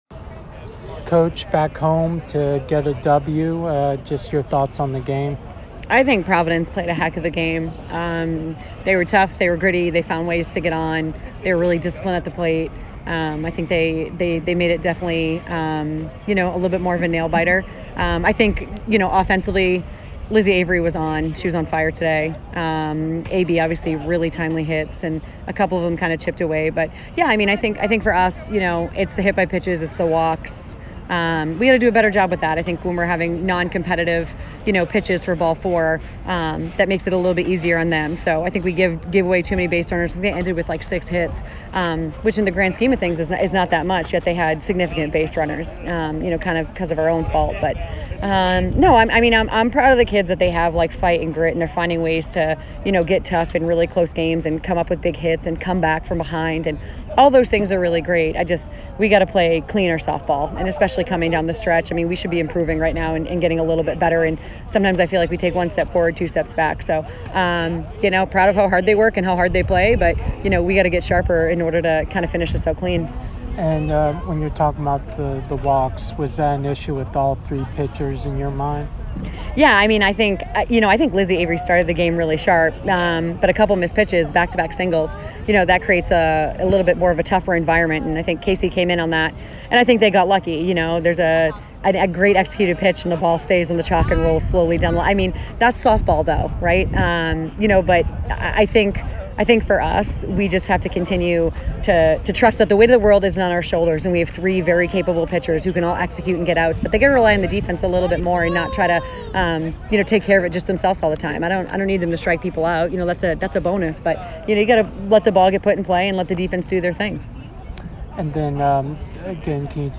Providence Postgame Interview